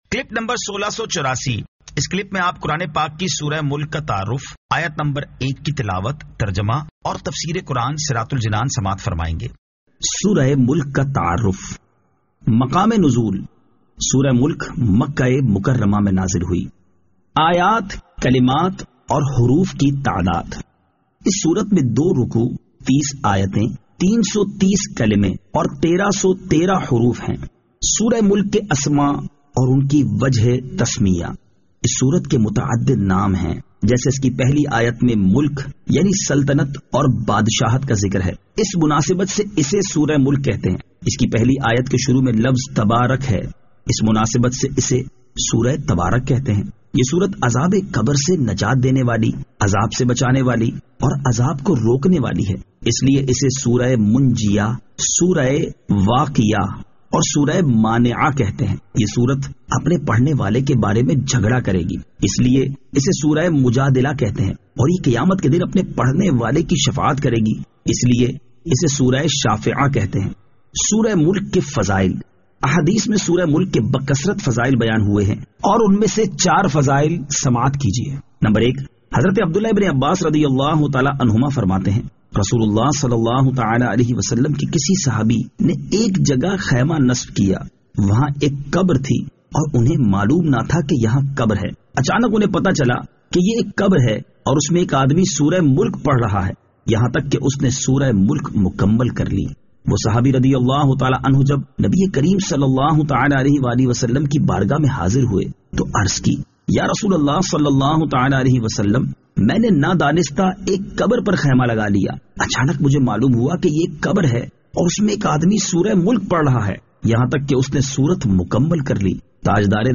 Surah Al-Mulk 01 To 01 Tilawat , Tarjama , Tafseer